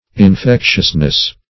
Infectiousness \In*fec"tious*ness\, n. The quality of being infectious.